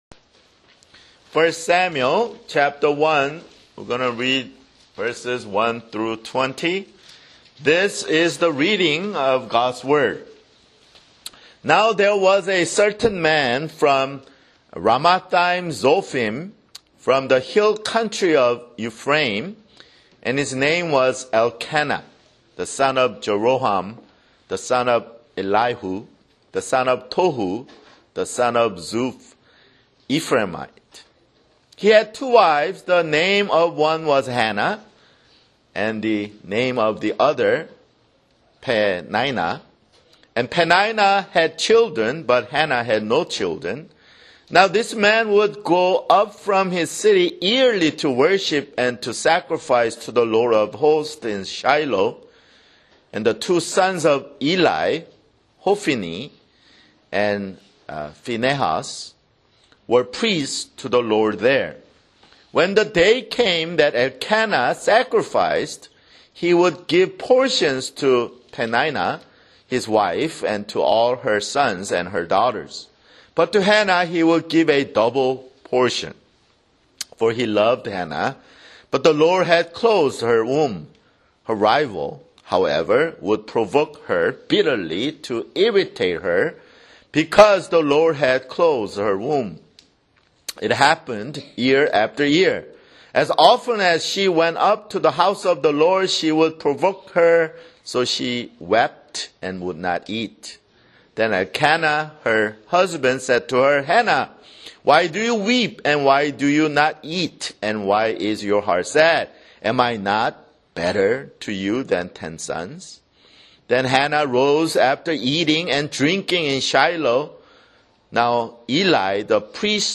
[Sermon] 1 Samuel (6)